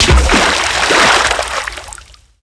impactwaterlarge03.wav